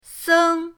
seng1.mp3